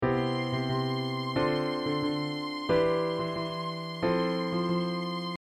Soprano-Pedal
Soprano-Pedal.mp3